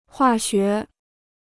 化学 (huà xué): chemistry; chemical.